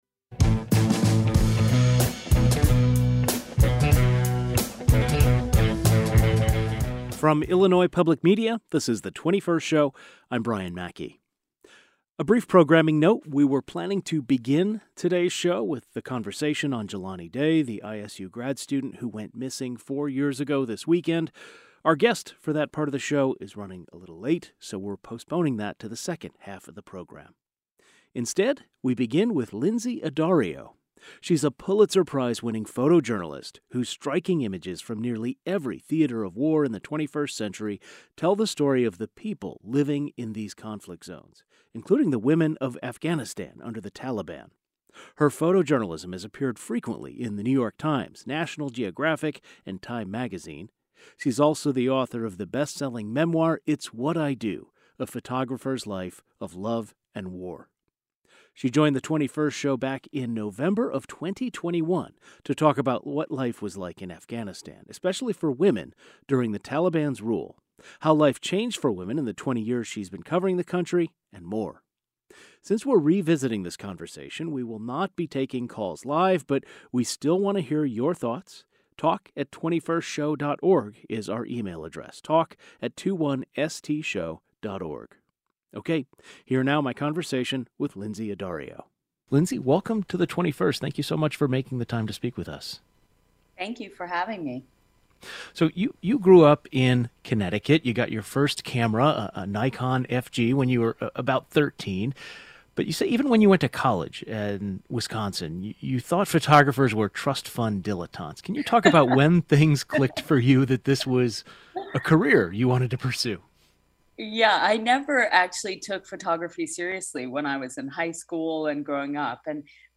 Lynsey Addario is a Pulitzer Prize winning photojournalist whose striking images from nearly every theater of war in the 21st century tell the story of the people living in these conflict zones. This conversation originally aired November 18, 2021.